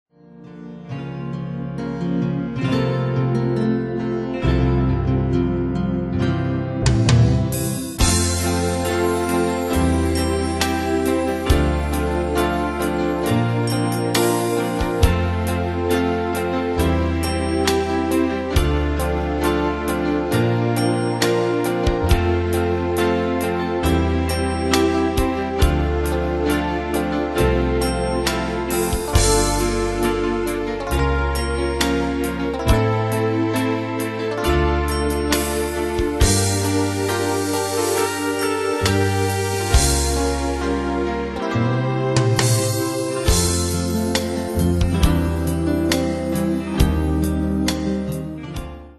Demos Midi Audio
Danse/Dance: Ballade Cat Id.
Pro Backing Tracks